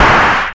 Index of /90_sSampleCDs/USB Soundscan vol.11 - Drums Dance & Groove [AKAI] 1CD/Partition B/04-ALL CLAPS
TRA02CLAP.wav